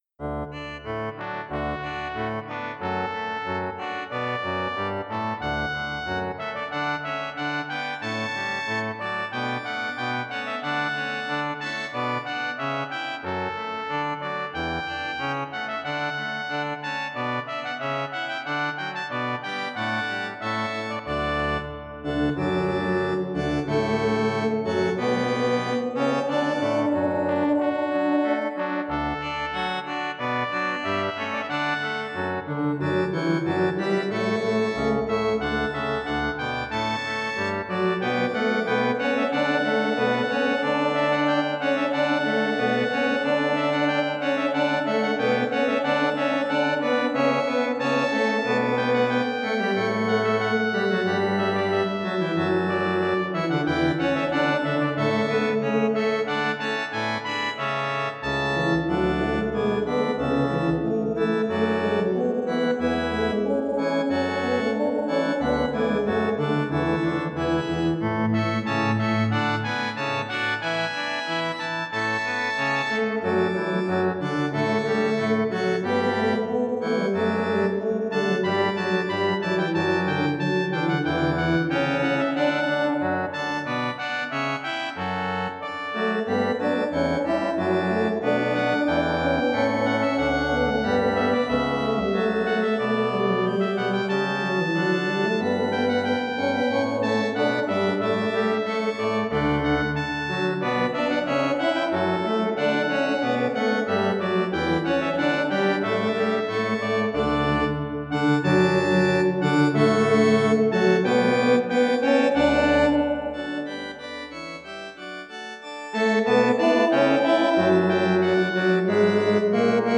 edition for Bass Voice, Trumpet and Organ